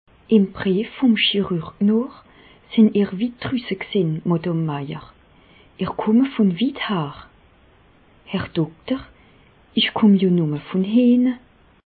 Bas Rhin
Ville Prononciation 67
Reichshoffen